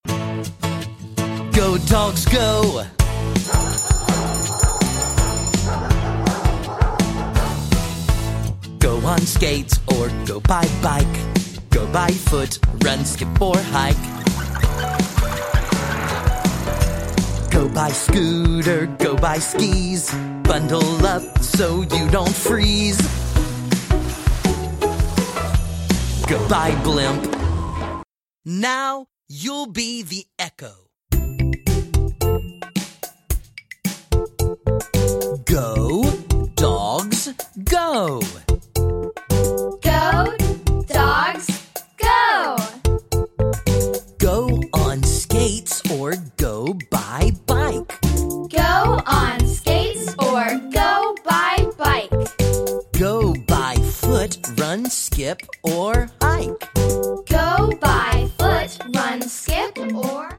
2. Story Song
3. Story Reading
5. Echo-Chant
6. Song with Children